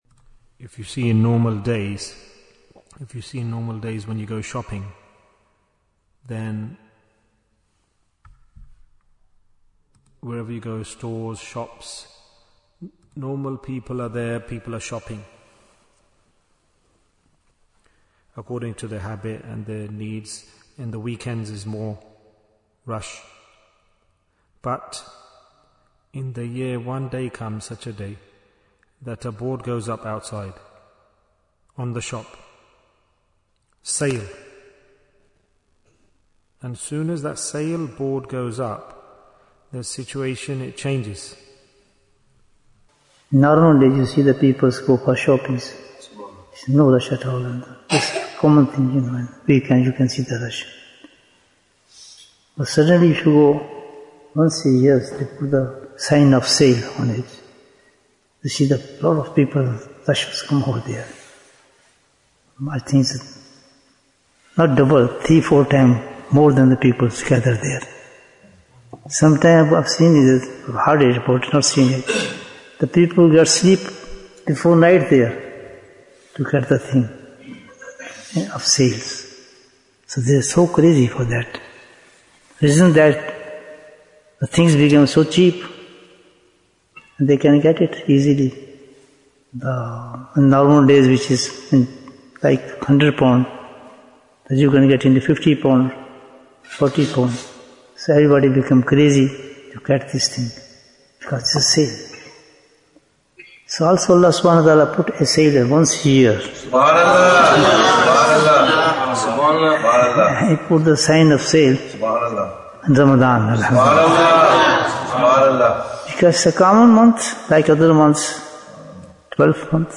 Jewels of Ramadhan 2026 - Episode 21 Bayan, 26 minutes1st March, 2026